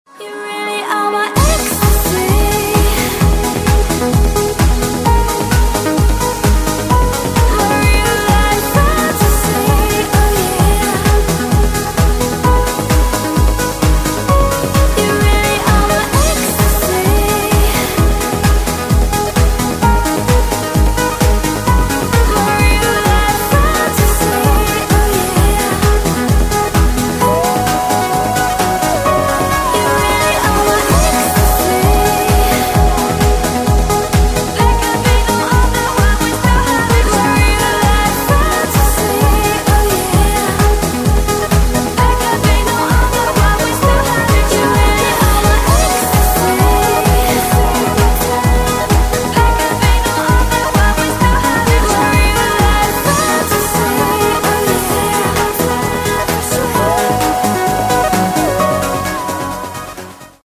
• Качество: 128, Stereo
громкие
dance
Electronic
EDM
электронная музыка
Downtempo
club
красивый женский голос
Trance